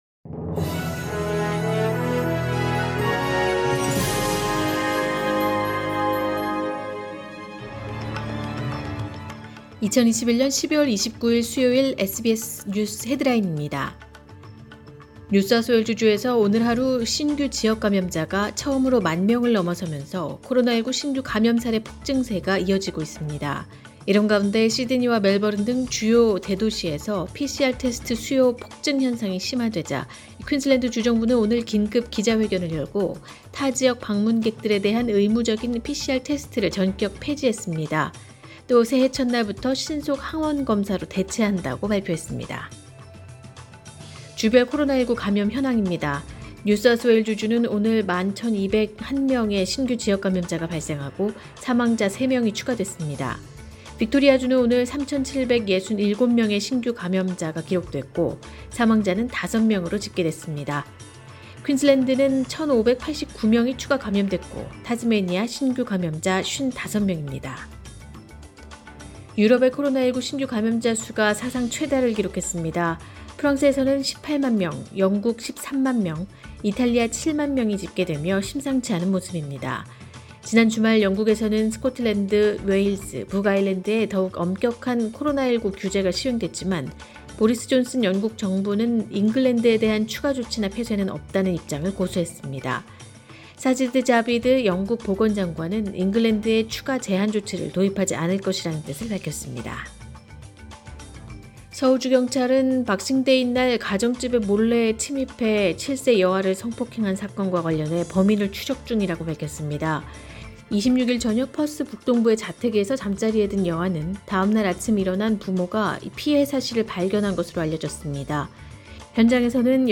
2021년 12월 29일 수요일 오전의 SBS 뉴스 헤드라인입니다.